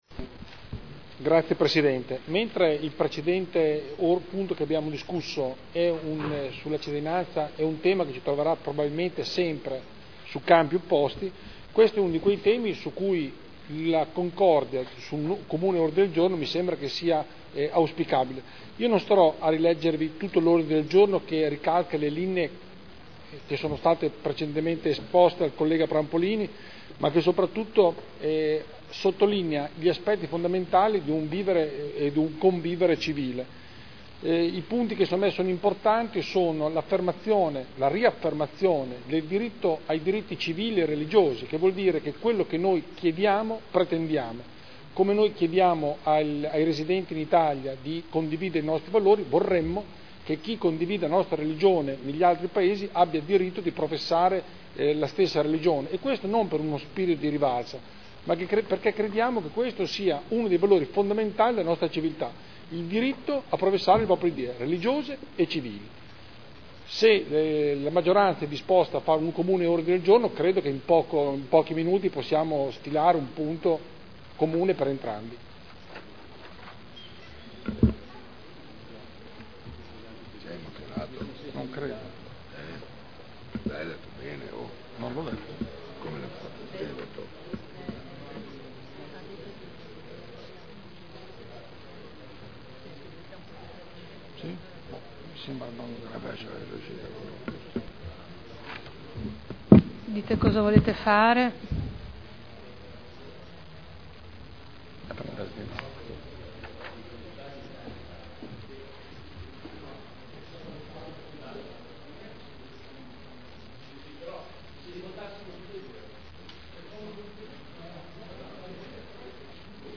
Andrea Galli — Sito Audio Consiglio Comunale